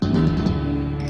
audio aquí), por la guitarra (